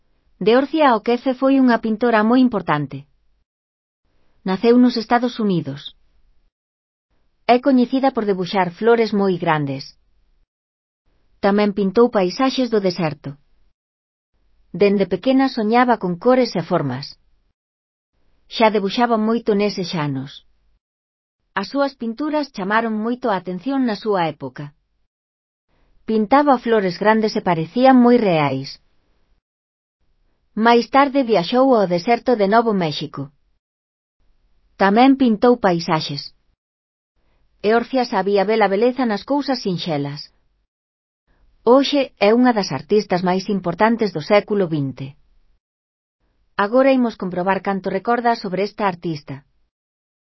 Elaboración propia (proxecto cREAgal) con apoio de IA, voz sintética xerada co modelo Celtia..